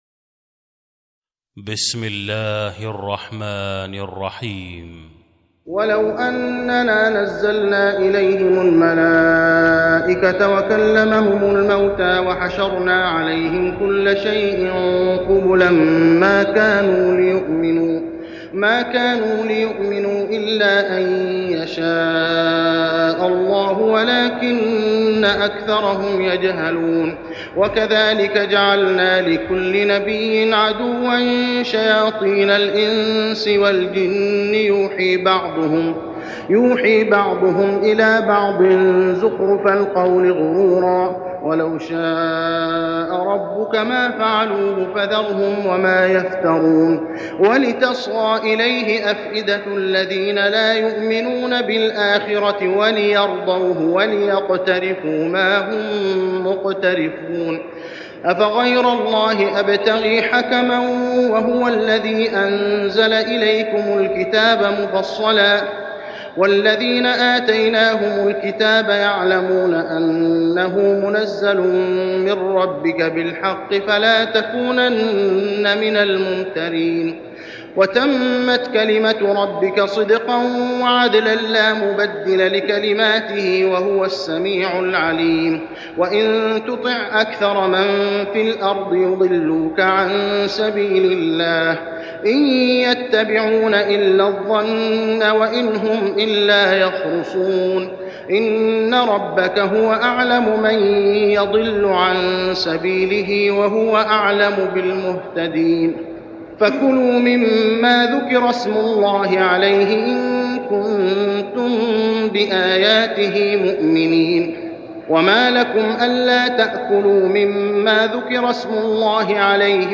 صلاة التراويح ليلة 9-9-1410هـ سورتي الأنعام 111-165 و الأعراف 1-30 | Tarawih prayer Surah Al-An'am And Al-A'raf > تراويح الحرم المكي عام 1410 🕋 > التراويح - تلاوات الحرمين